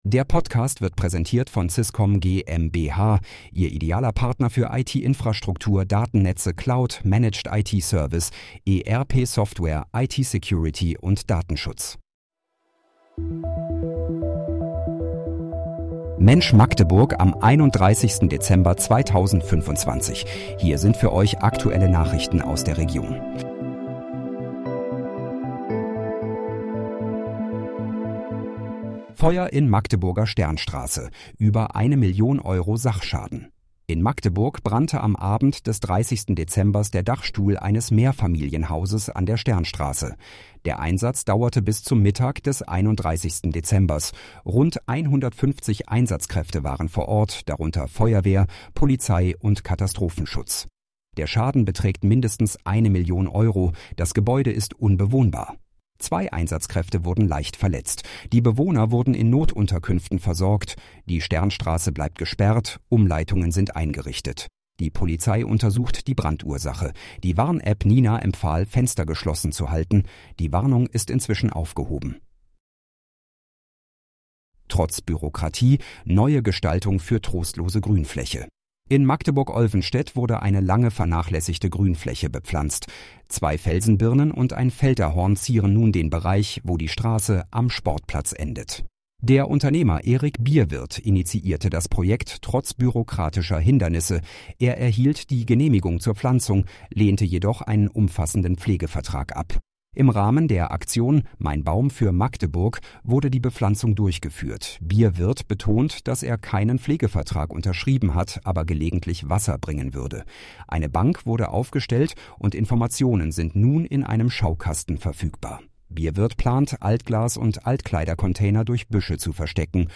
Mensch, Magdeburg: Aktuelle Nachrichten vom 31.12.2025, erstellt mit KI-Unterstützung